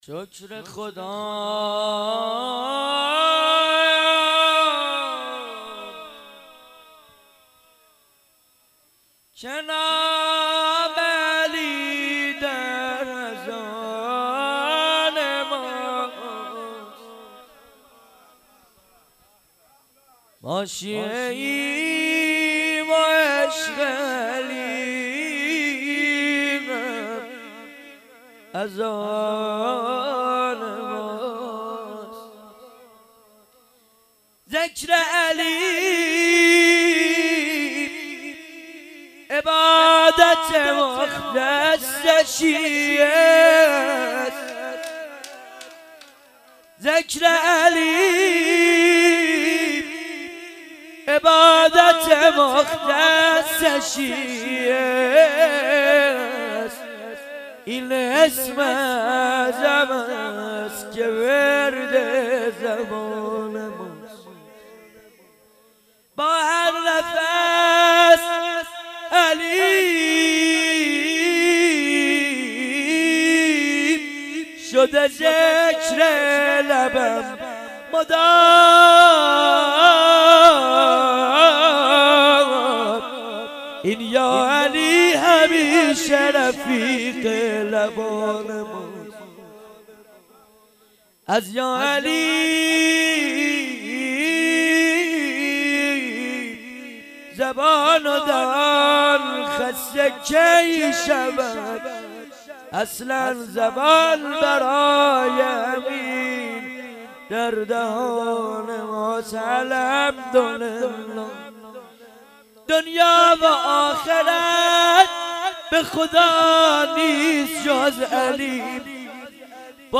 مراسم تخریب بقیع
(شور)